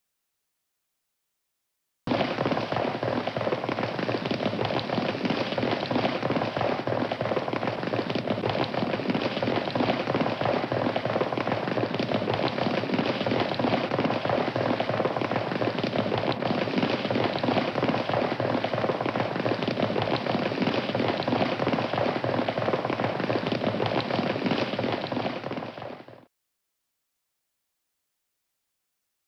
دانلود صدای دویدن اسب – یورتمه اسب 3 از ساعد نیوز با لینک مستقیم و کیفیت بالا
جلوه های صوتی